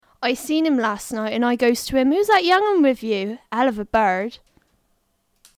Examples of Cornish English
//ɑɪ siːn ɪm læst nɑɪt ənd ɑɪ gɔʊz tu ɪm/huːz ðæt jʌŋən wɪv juː/ɛl əv ə bɛɻd //
The pronunciation of one as /ən/ is also not restricted to this dialect.
Notice the retroflex "r" in bird.